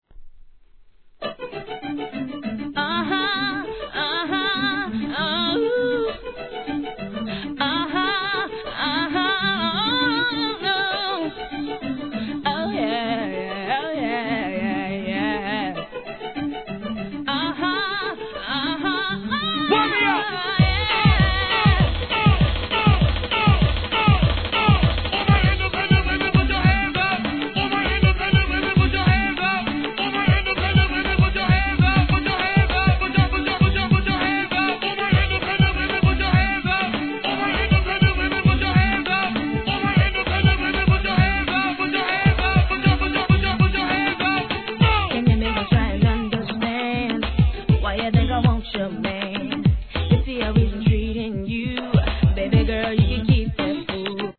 HIP HOP/R&B
3LW路線のキャッチーさに加えて、のっけからMCの煽りも入る代物。